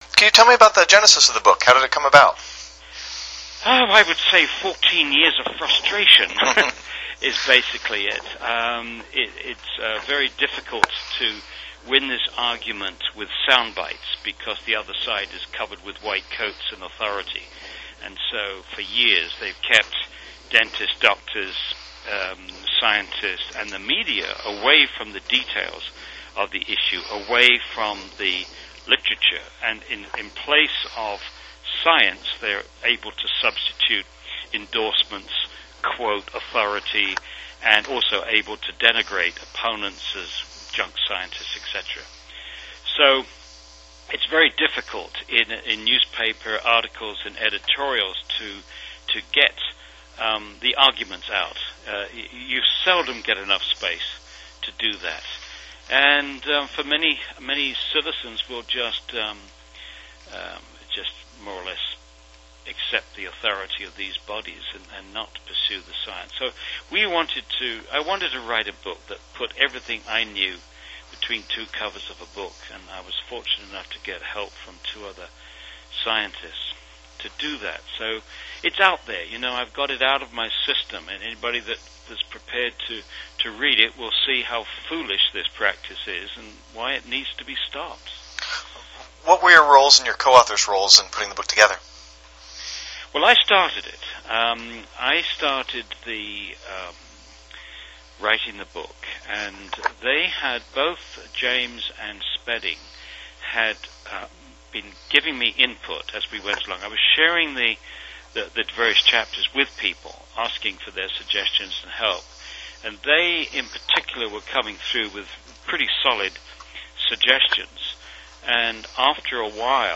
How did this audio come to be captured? in a phone interview last week.